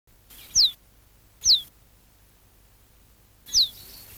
Trekroepjes Gorzen
rietgors.mp3